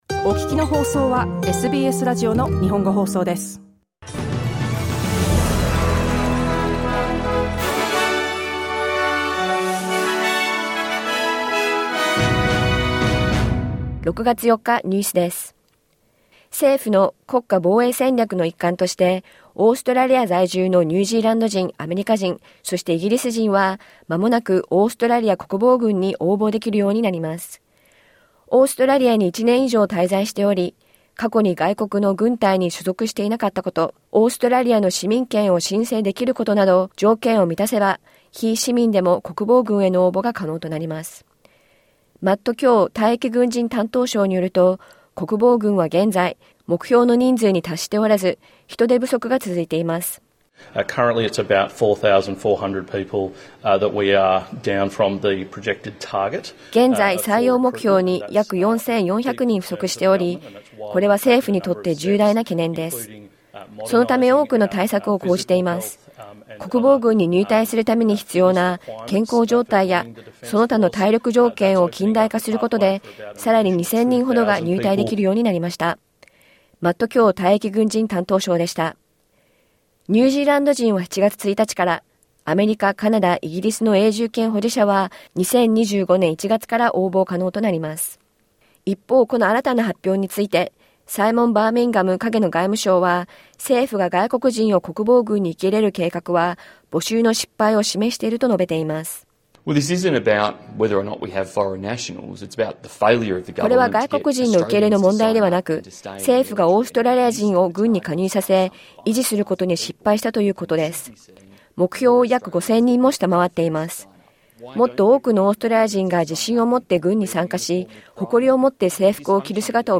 SBS日本語放送ニュース6月4日火曜日